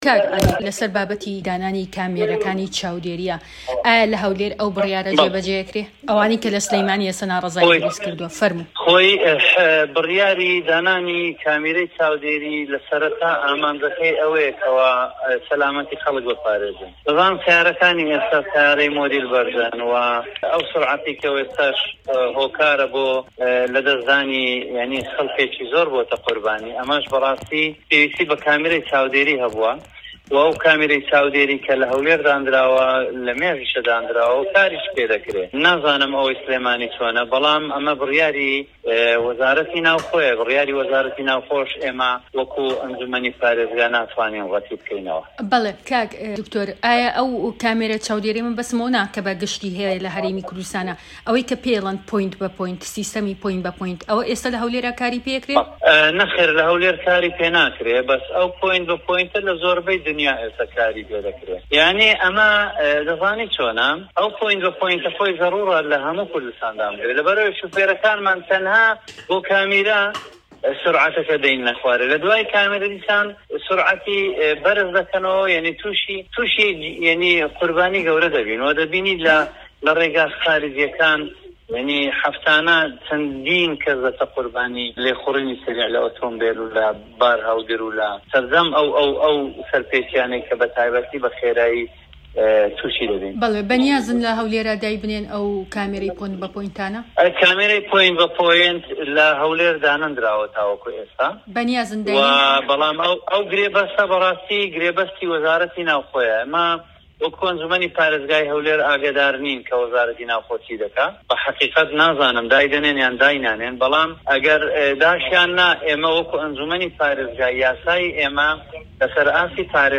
د. عەلی ڕەشید سەرۆکی ئەنجومەنی پارێزگای هەولێر
دەقی وتووێژەکە لەگەڵ د. عەلی ڕەشید